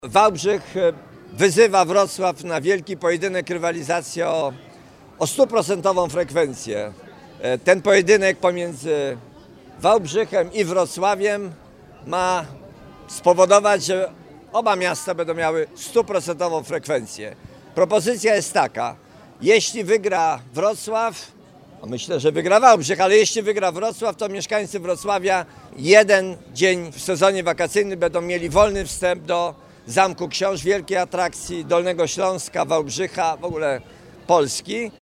Prezydent Wałbrzycha wystąpił z propozycją: jeśli Wrocław wygra pojedynek to mieszkańcy stolicy Dolnego Śląska będą mogli bezpłatnie zwiedzać Zamek Książ – jedną z największych atrakcji regionu, prawdziwą perłę Wałbrzycha i całej Polski. Mówi Roman Szełemej.